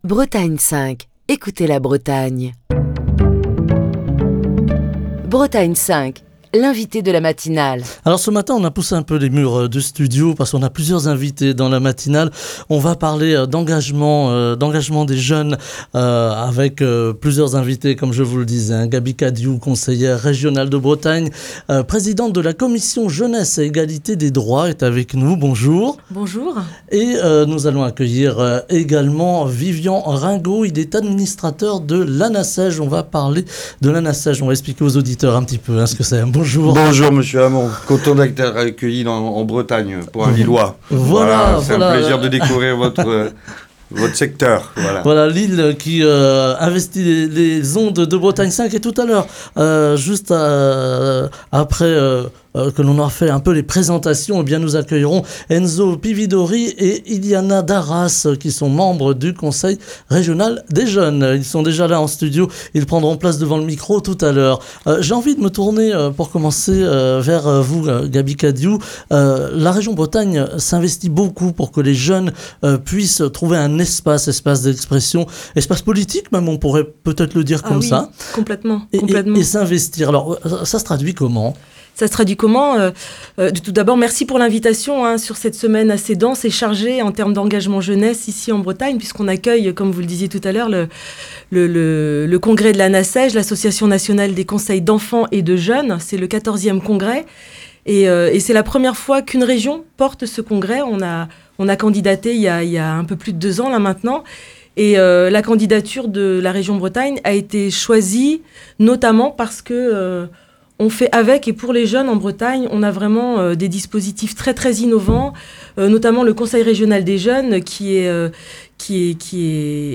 Ce matin, nous parlons de l'engagement des jeunes dans la vie publique, avec un format un peu particulier pour l'entretien de la matinale de Bretagne 5, qui se fait à quatre voix.